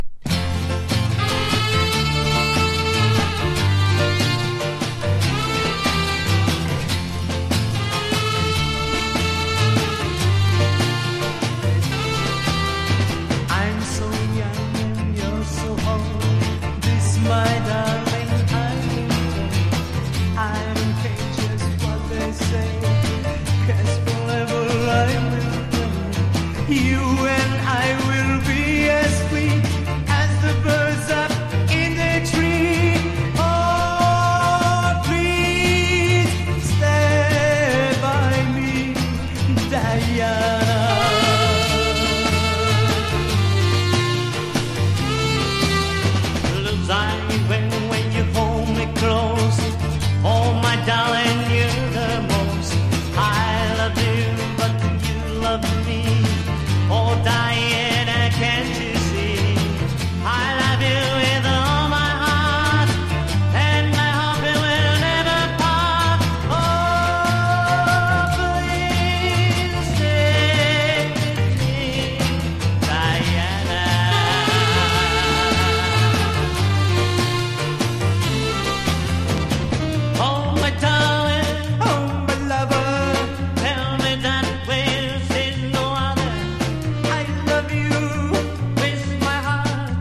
# 60-80’S ROCK# 和モノ